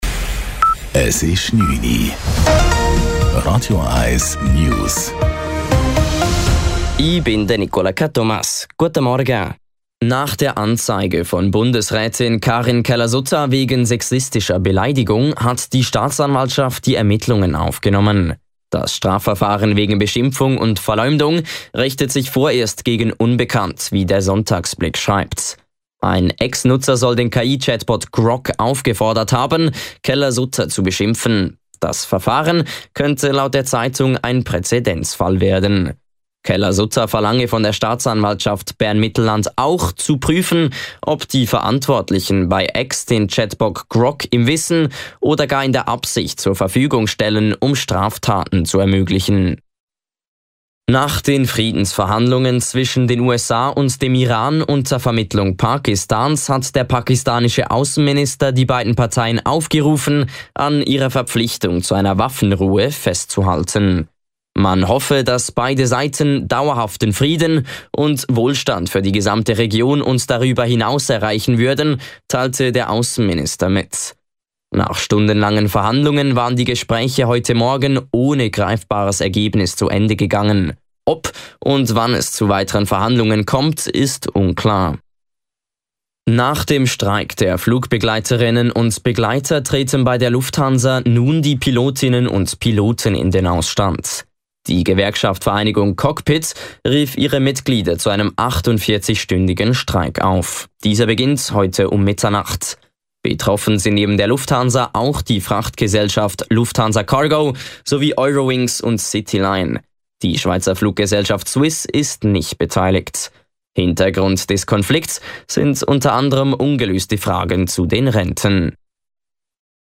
Die aktuellsten News von Radio 1 - kompakt, aktuell und auf den Punkt gebracht.